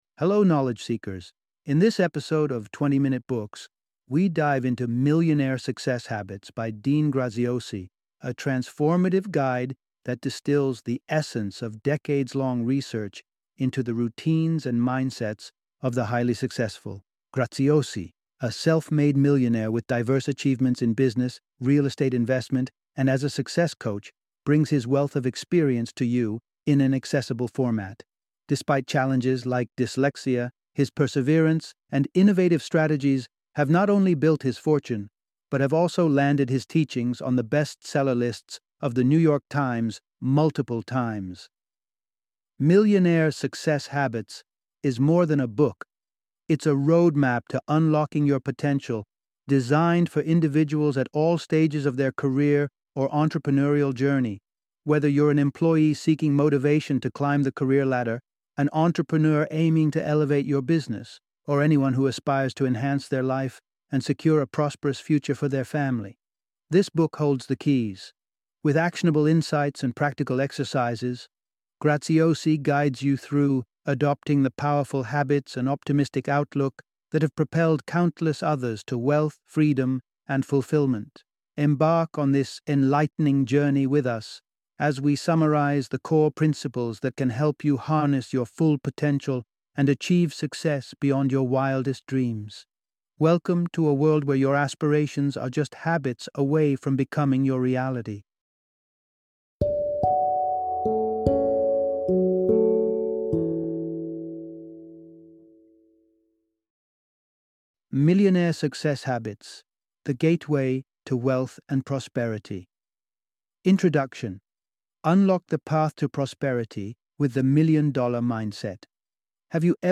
Millionaire Success Habits - Audiobook Summary